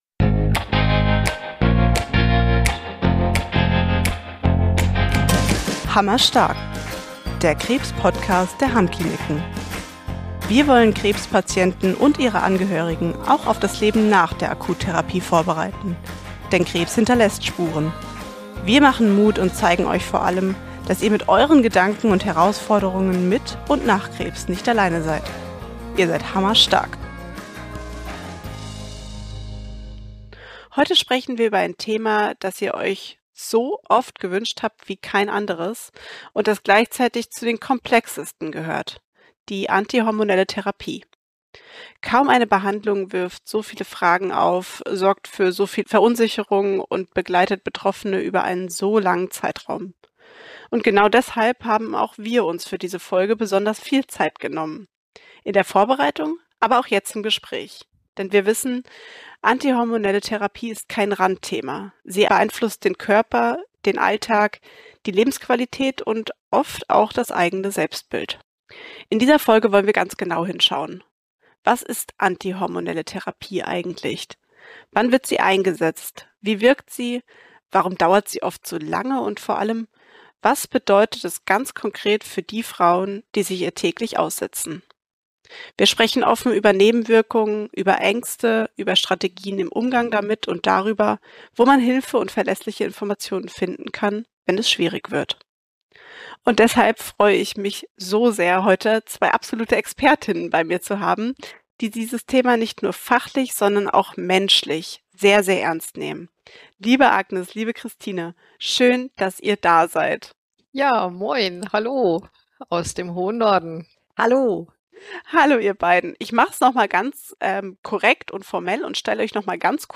Gemeinsam mit zwei erfahrenen Gynäkologinnen sprechen wir offen über Wirkung, Nebenwirkungen und ganz konkrete Strategien für den Alltag.